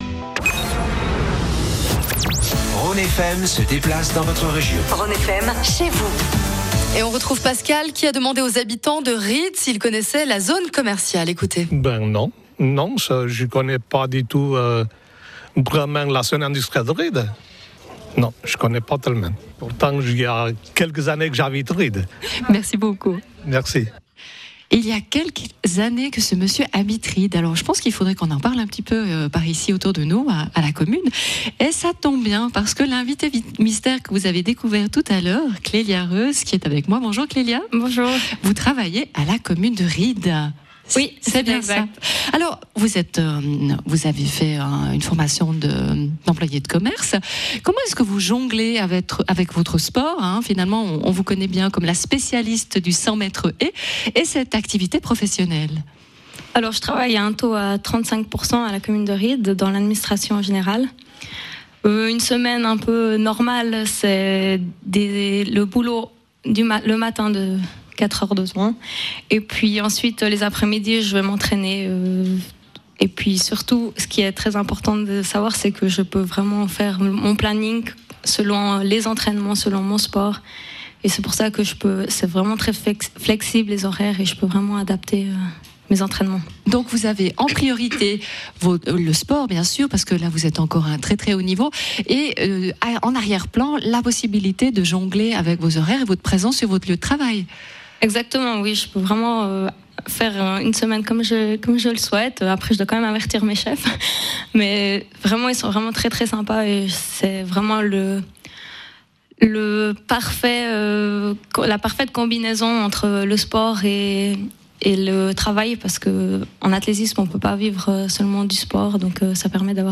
Après une présentation du tissu économique par le Président de commune, les interviews en direct de plusieurs entrepreneurs vous permettront de mieux connaitre une commune et son économie !
La deuxième diffusion a eu lieu le mardi 20 octobre 2015, à Riddes, entre 13h00 et 16h00 :
Interview de l'invitée mystère